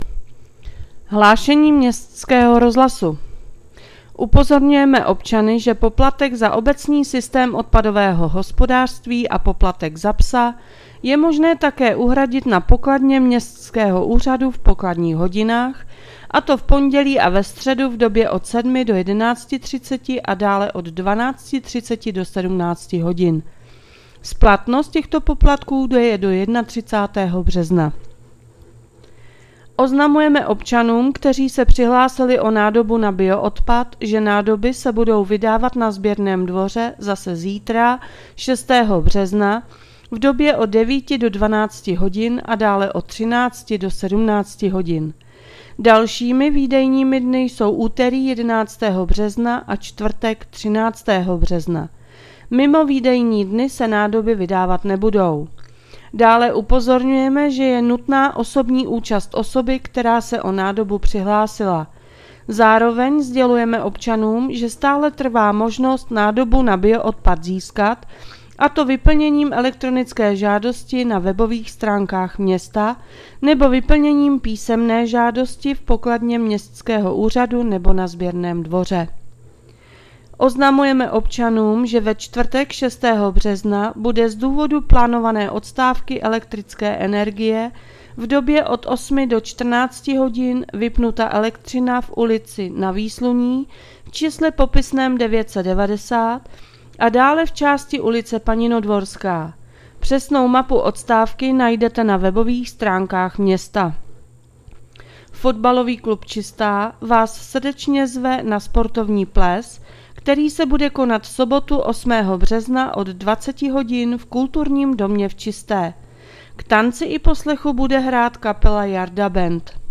Hlášení městského rozhlasu 5.4.2025